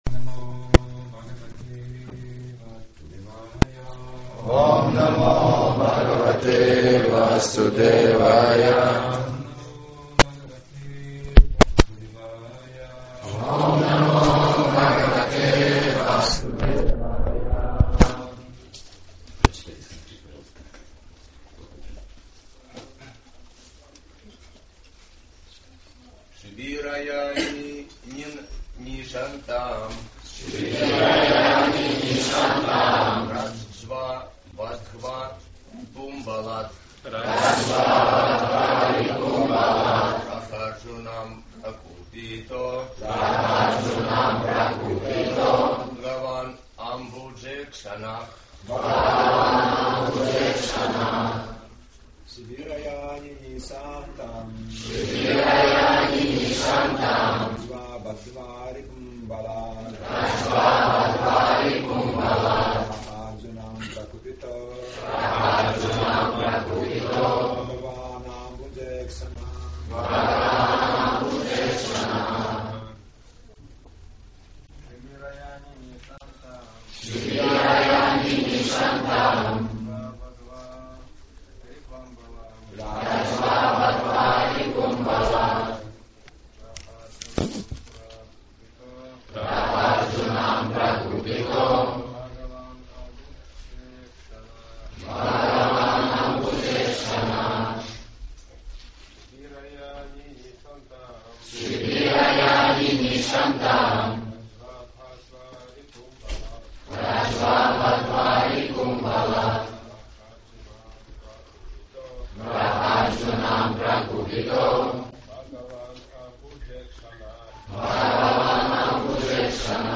Темы, затронутые в лекции